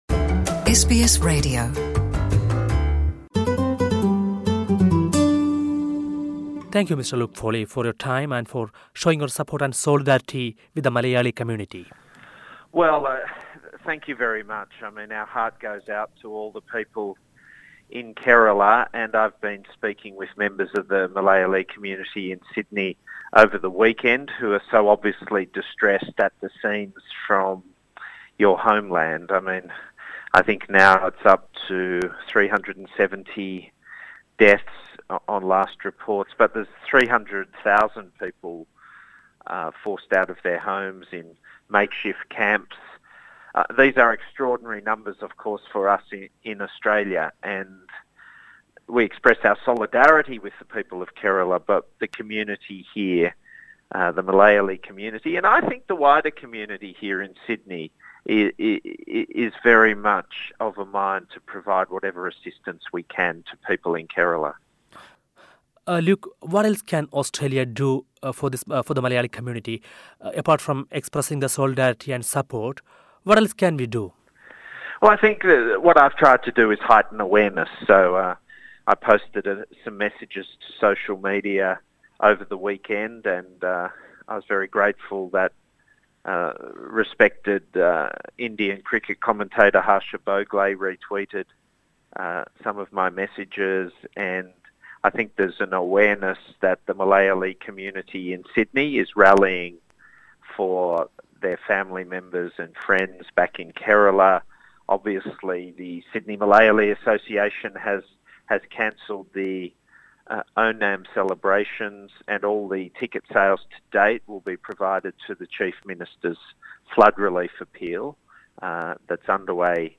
NSW Labor party leader Luke Foley has said that it would be highly appropriate for Australia to provide assistance to the flood-hit southern Indian state Kerala, where more than 360 people died and over 800,000 are homeless. In an interview with SBS Malayalam Radio, he expressed his solidarity with the Malayalee community in Australia.